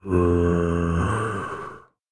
assets / minecraft / sounds / mob / zombie / say3.ogg